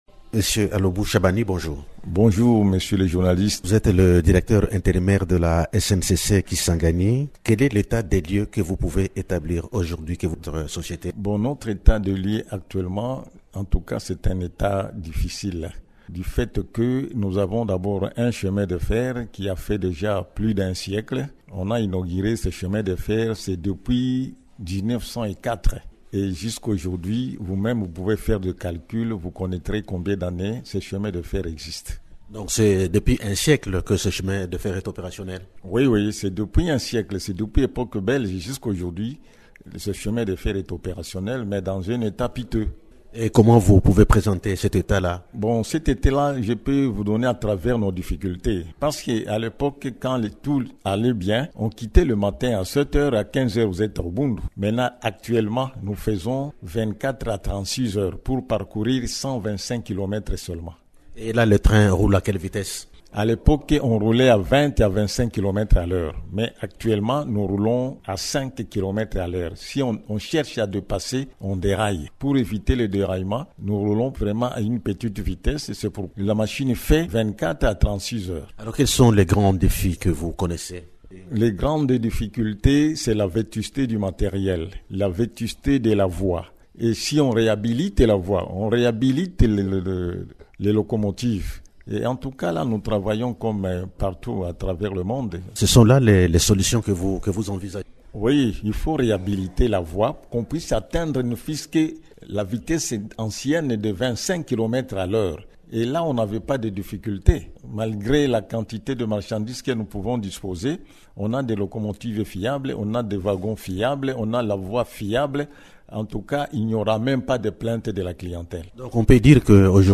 Invité de Radio Okapi, il signale que le train de passagers est suspendu depuis 2019.